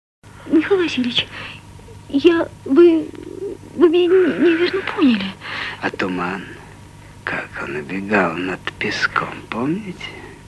• Качество: 128, Stereo
мужской голос
женский голос
Небольшой диалог из старого русского фильма